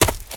STEPS Leaves, Run 11.wav